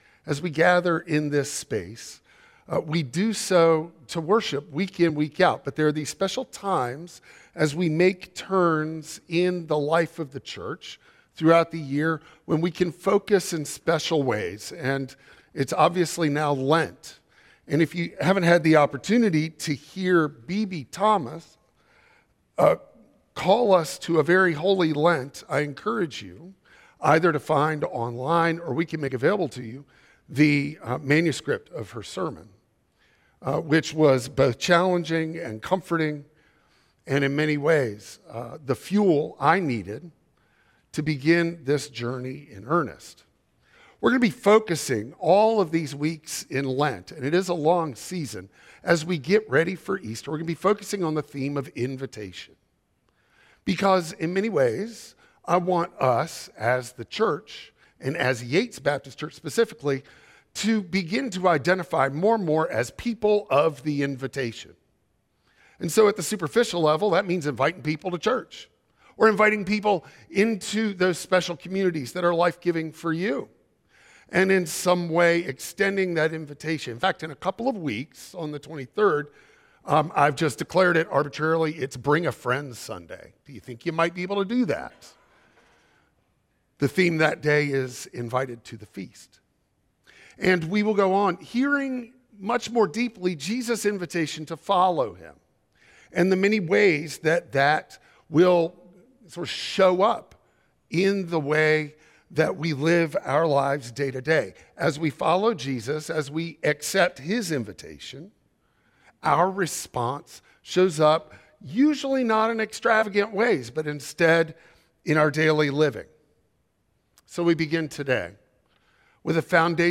Luke 4:1-13 Service Type: Traditional Service Release your grip.